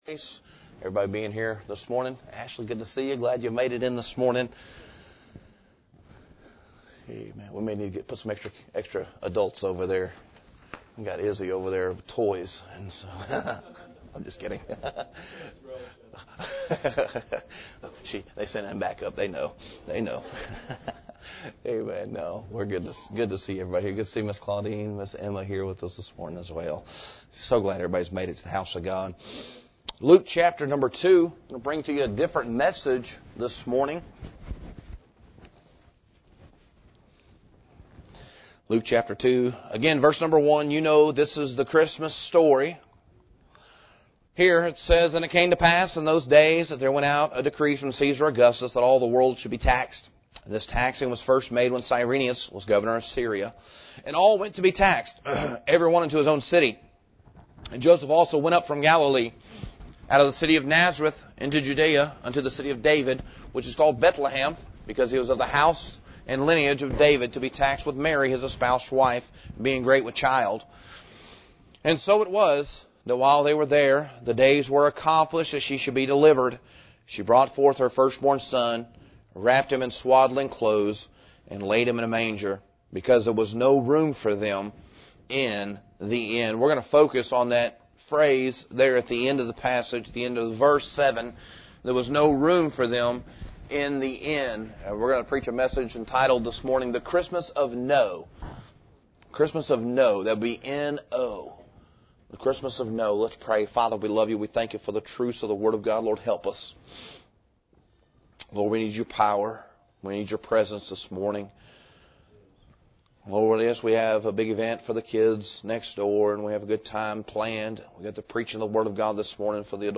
This sermon — “The Christmas of No” — examines three ways the world, and often we, respond with “no” to God: no free space, no fear (mean